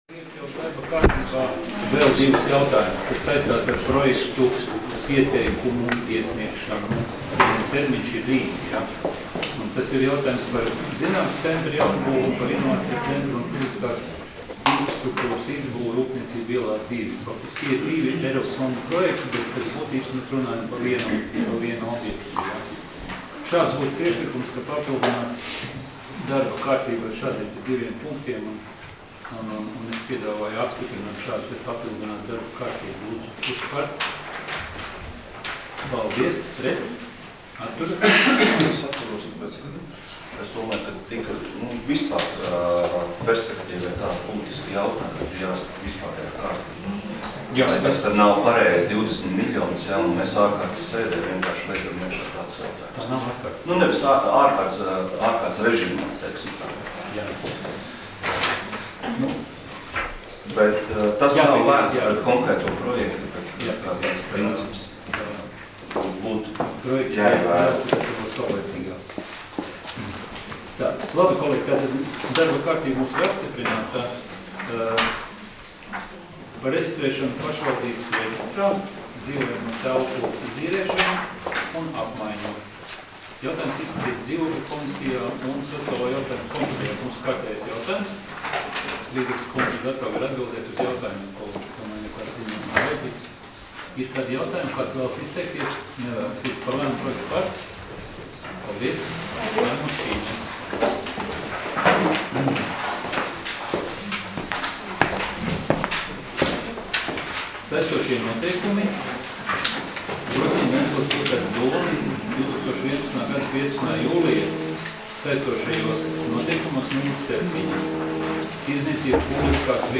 Domes sēdes 14.03.2019. audioieraksts